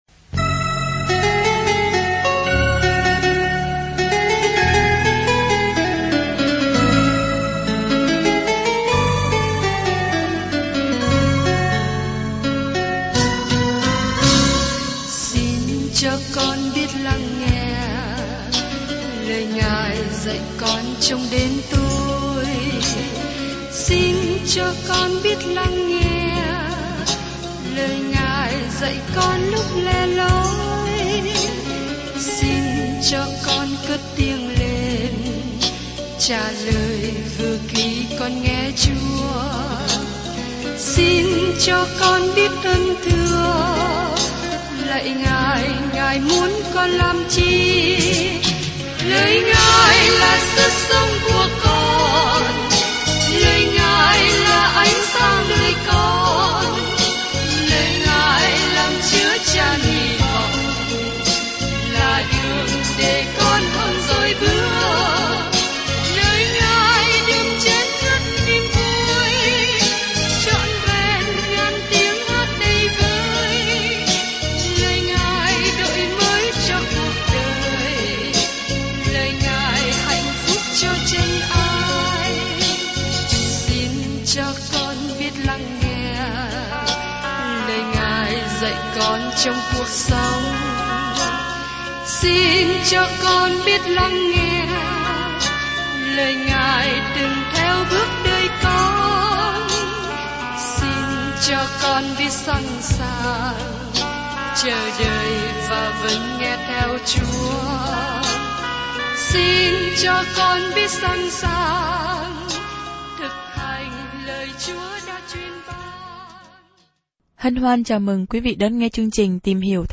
Bài giảng … là một phần của chương trình “Tìm Hiểu Thánh Kinh”.